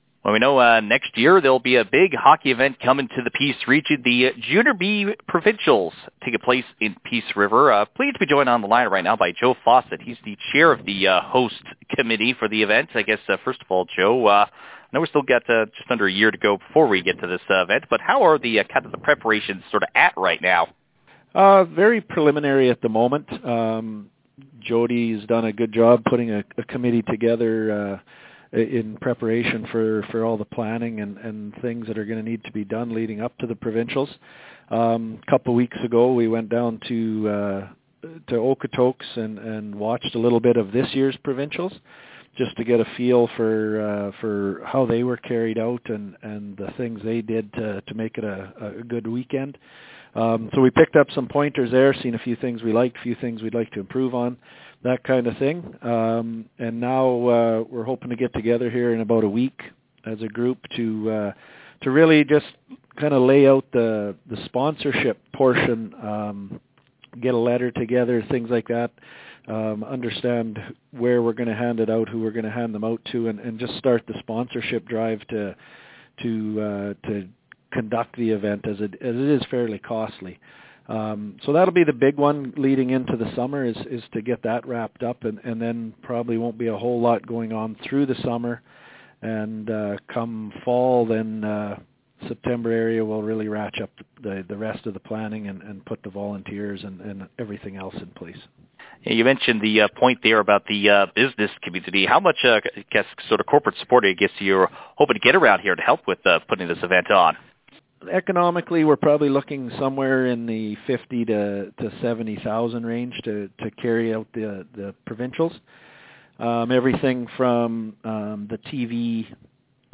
The full interview